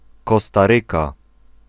Ääntäminen
Ääntäminen US UK : IPA : /ˌkɒs.tə ˈɹiː.kə/ US : IPA : /ˌkoʊ.stə ˈɹi.kə/ IPA : /ˌkɑ.stə ˈɹi.kə/ Lyhenteet CR Haettu sana löytyi näillä lähdekielillä: englanti Käännös Ääninäyte Erisnimet 1. Kostaryka {f} Määritelmät Erisnimet A country in Central America .